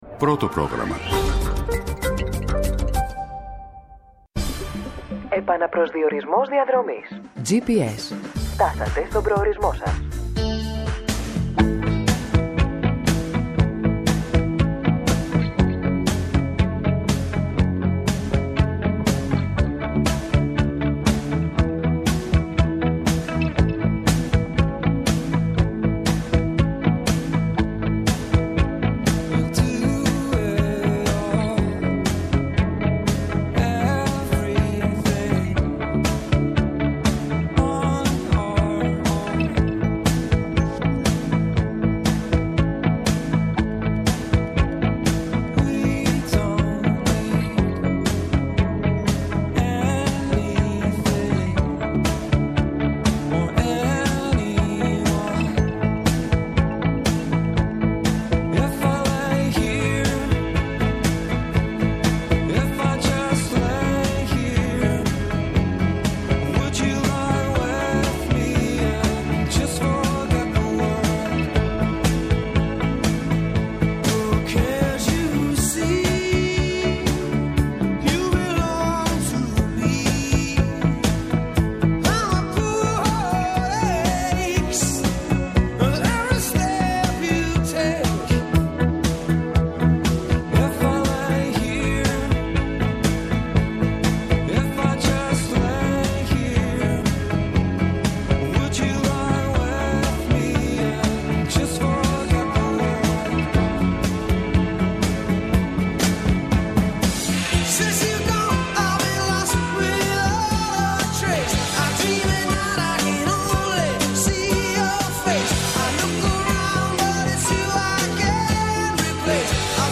-Ο Θεόφιλος Ξανθόπουλος, βουλευτής Δράμας του ΣΥΡΙΖΑ Π.Σ.